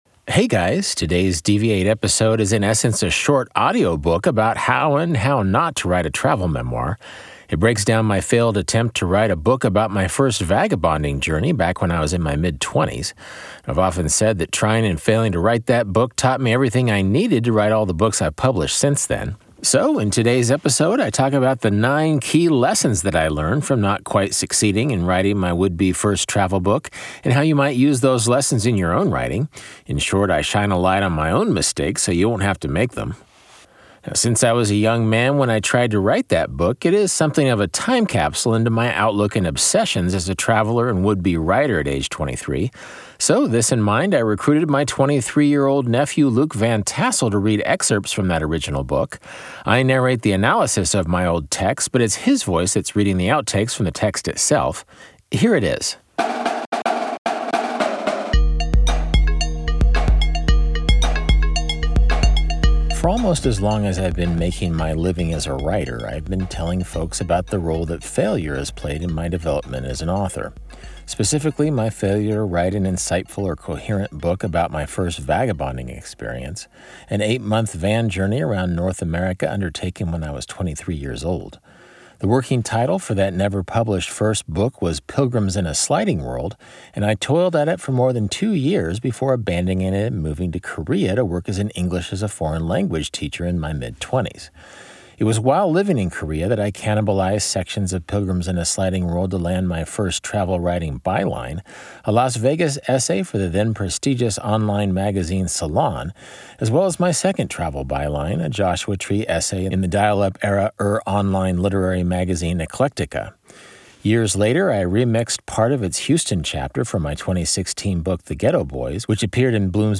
An audiobook about how (not) to write a travel book: 9 lessons from my failed van-life memoir